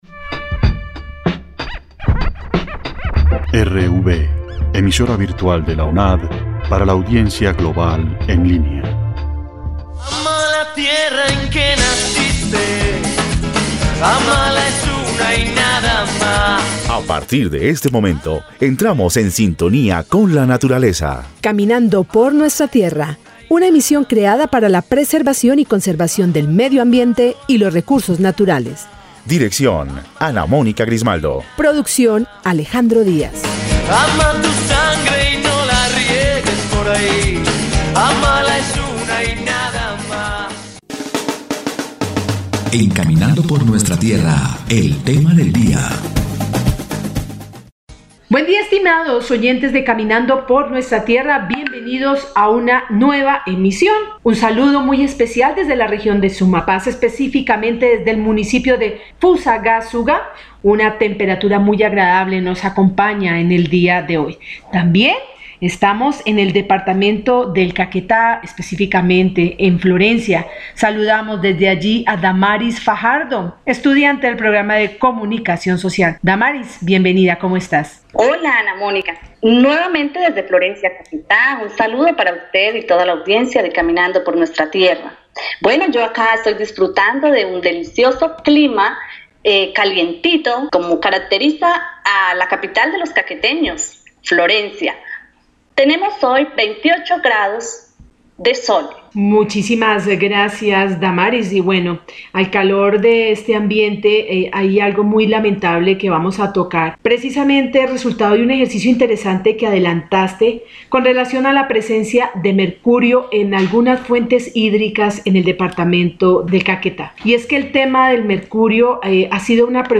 Programa radial